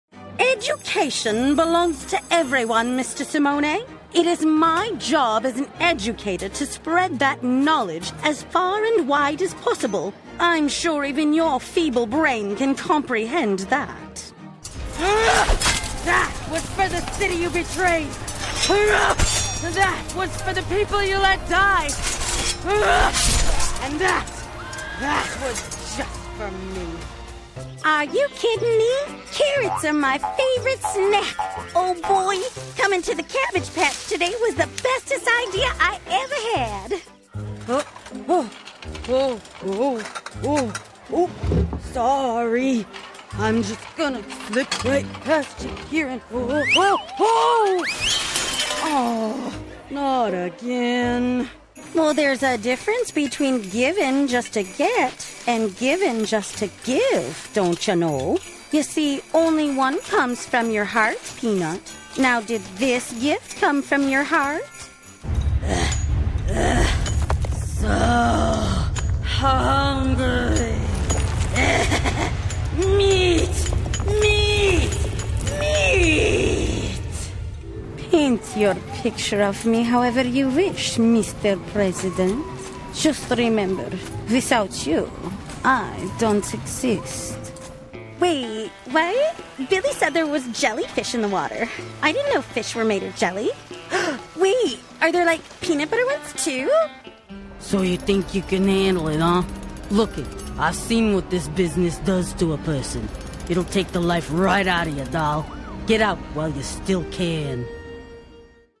Voice Artist
Bring life to characters with a fun cheerful voice, or a dramatic flair.